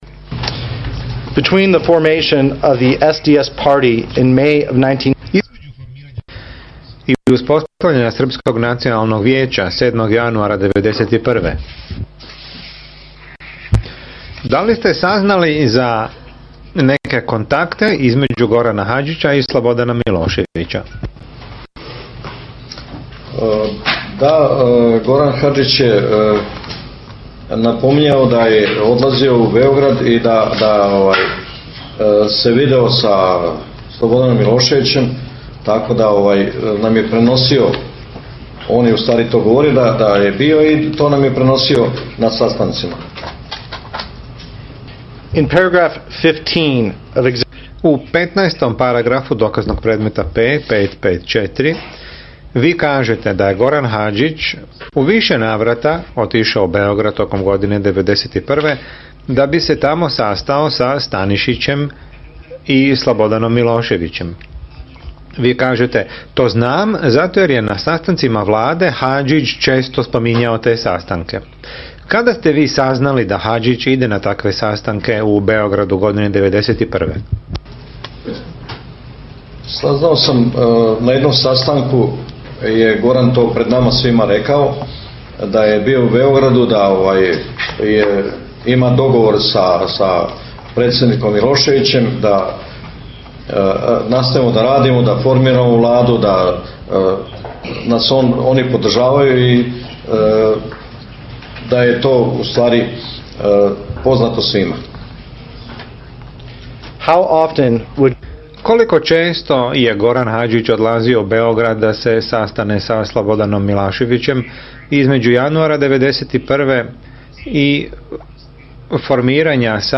Dio snimka Bogunovićevog iskaza na sudjenju Stanišiću 2010.godine